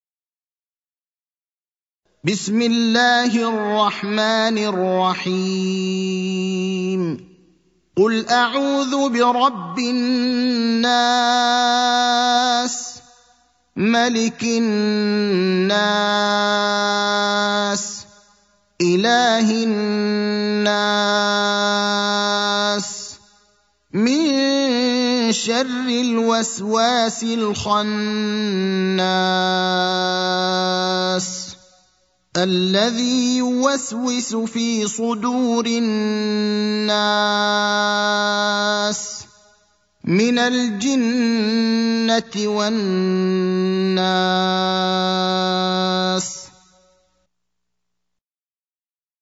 المكان: المسجد النبوي الشيخ: فضيلة الشيخ إبراهيم الأخضر فضيلة الشيخ إبراهيم الأخضر الناس (114)